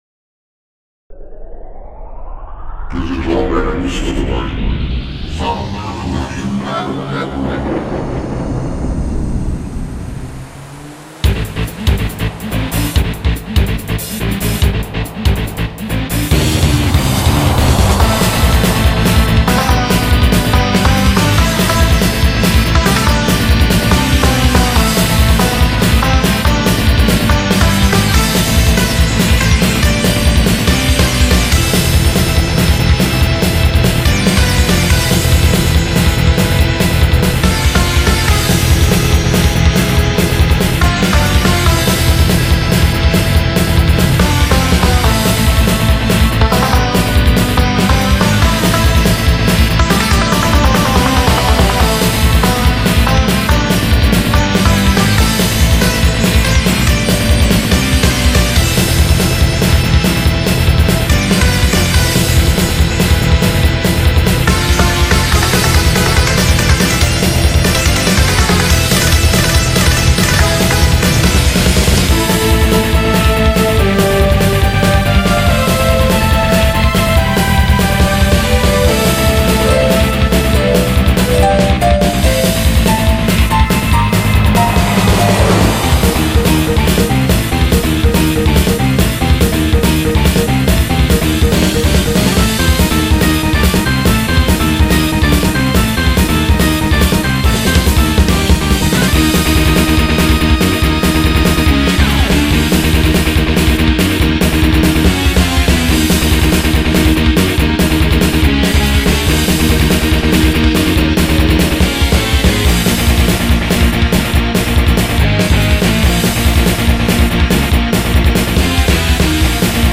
劣化コピーなメドレーです。